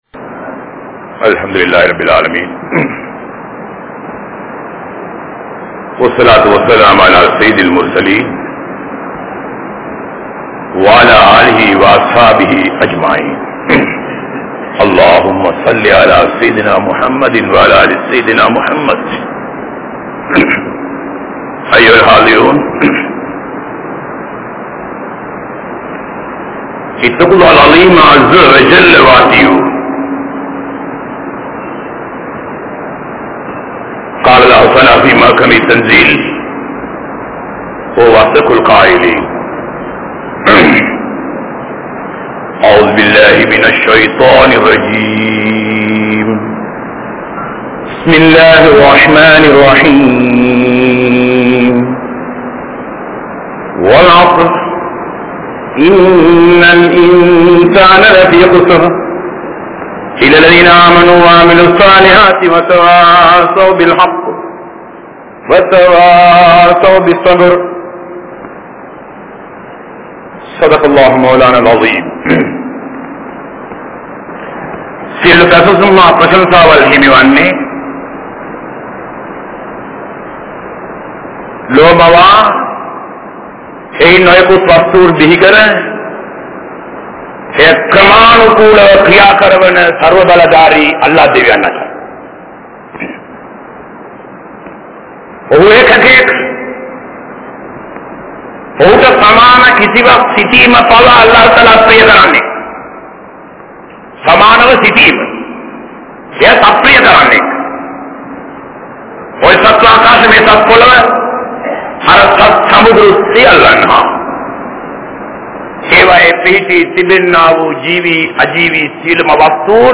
Thaqwa (தக்வா) | Audio Bayans | All Ceylon Muslim Youth Community | Addalaichenai
Hudha Jumua Masjidh